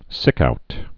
(sĭkout)